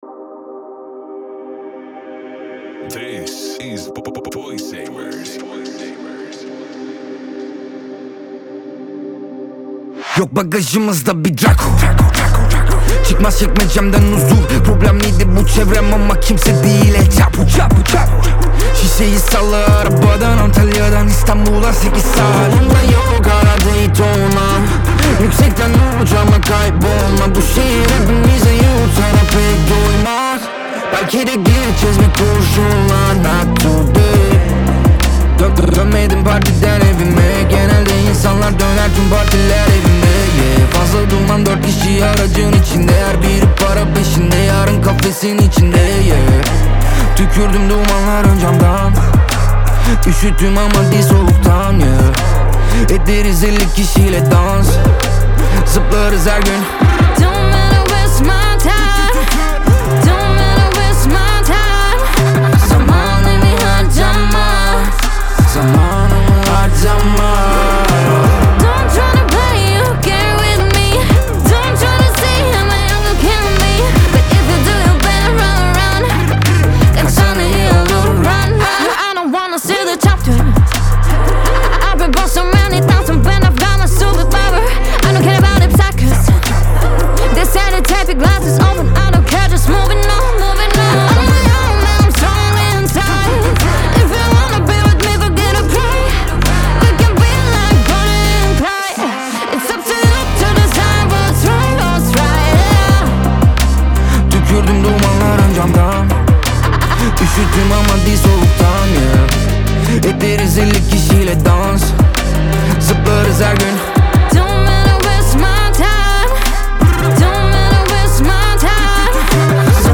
Трек размещён в разделе Турецкая музыка / Рэп и хип-хоп.